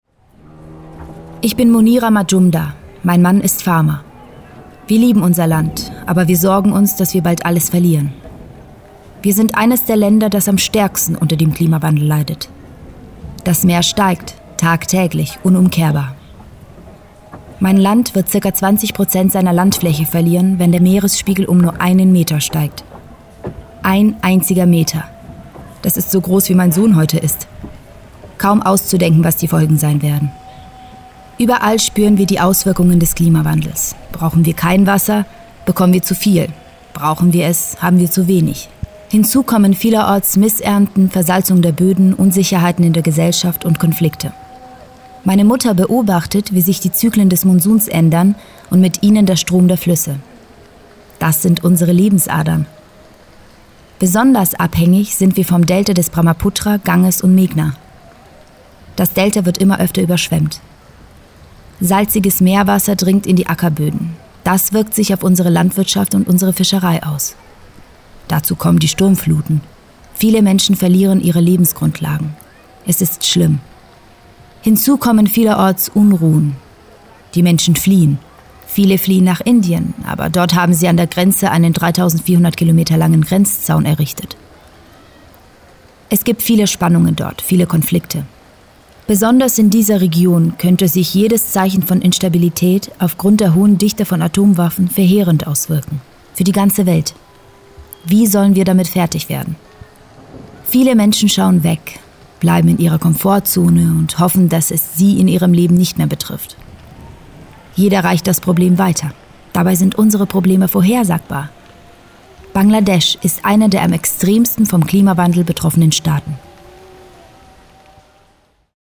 Eine Bäuerin erzählt: